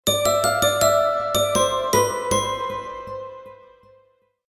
Chistmas Bellss Sound Effect Free Download
Chistmas Bellss